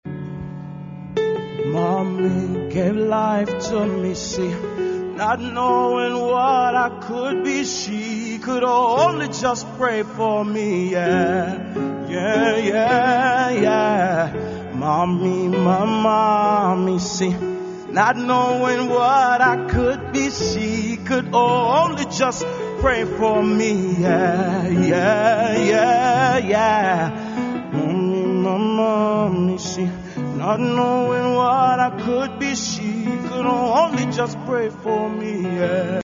Recorded in New York, USA, 2005